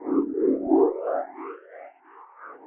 Index of /musicradar/rhythmic-inspiration-samples/90bpm